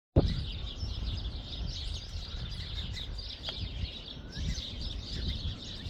birdsong.ogg